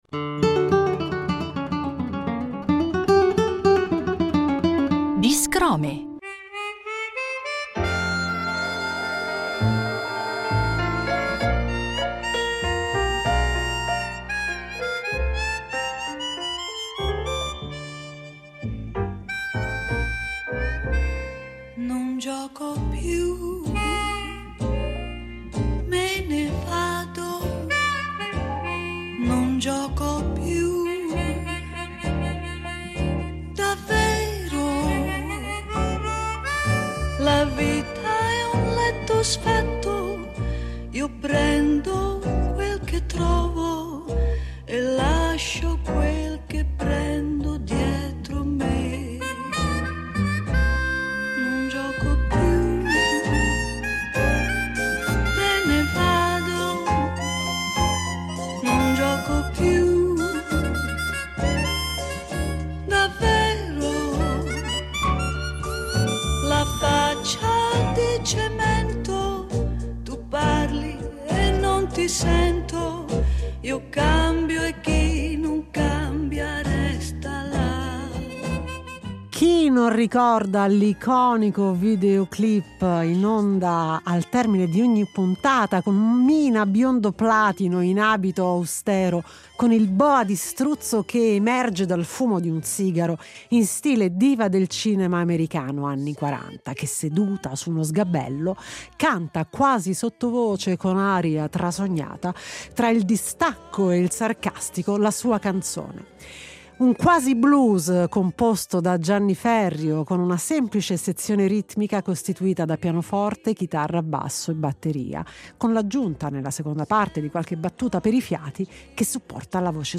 con la cantante, musicista e compositrice
un tributo in chiave jazz